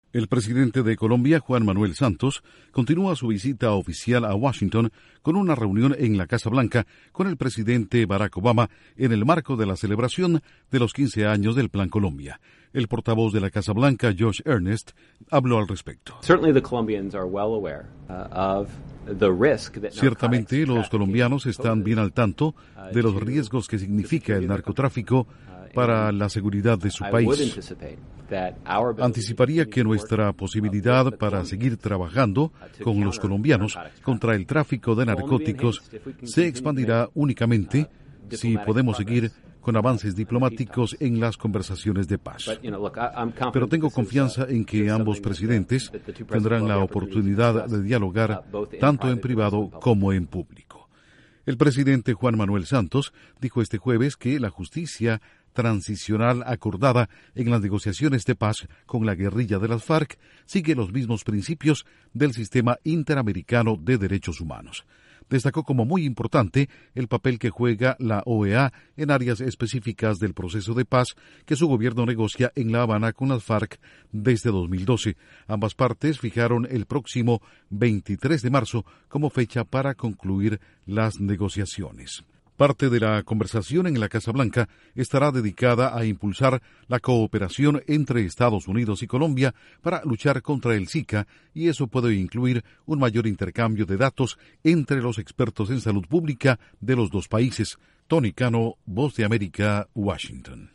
Presidentes de Estados Unidos y Colombia analizan en la Casa Blanca estrategias en la lucha contra las drogas, y el virus del Zika. Informa desde la Voz de América en Washington